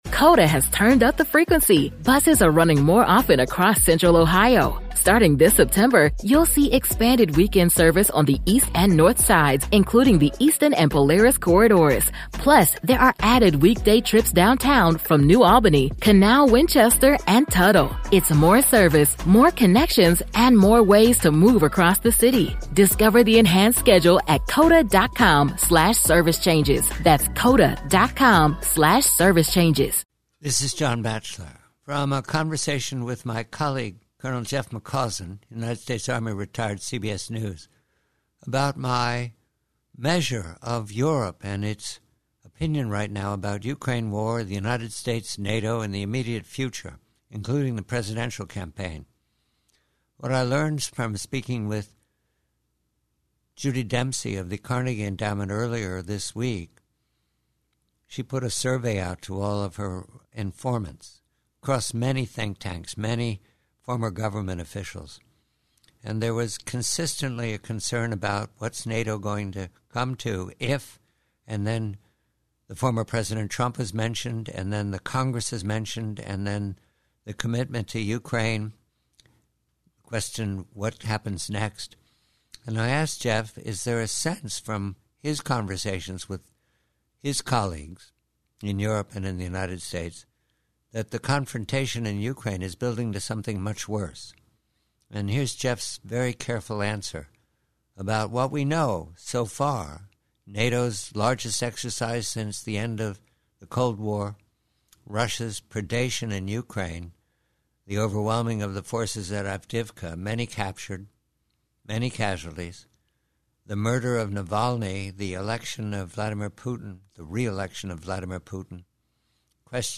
Excerpt from a conversation